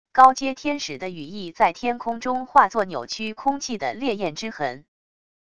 高阶天使的羽翼在天空中化作扭曲空气的烈焰之痕wav音频